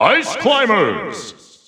The announcer saying Ice Climbers' names in English releases of Super Smash Bros. Ultimate.
Ice_Climbers_English_Announcer_SSBU.wav